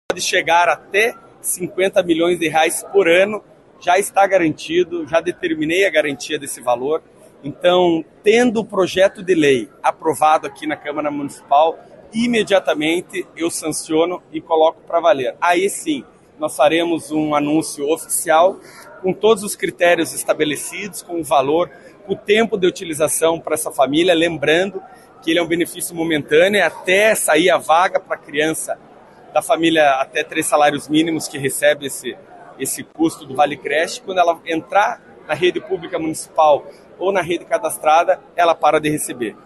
Durante entrevista concedida para a imprensa na sede do Legislativo Municipal, nesta segunda-feira (3), o prefeito Eduardo Pimentel (PSD) falou sobre a iniciativa.